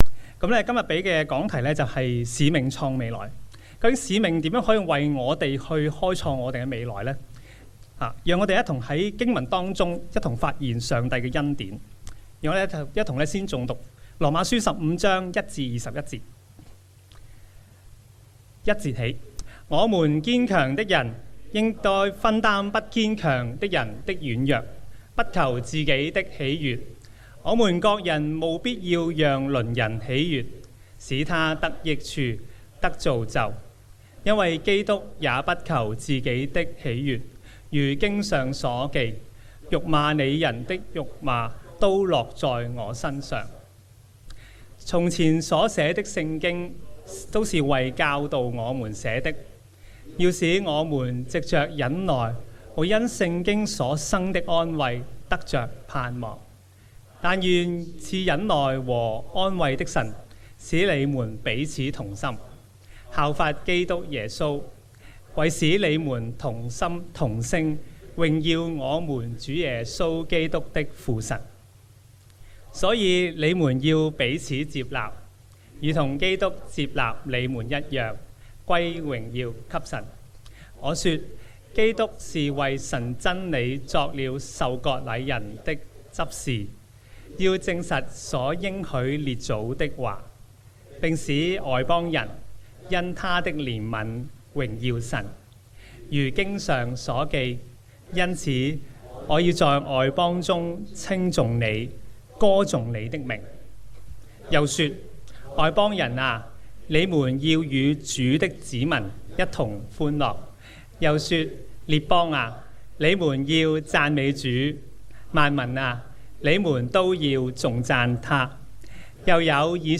證道重溫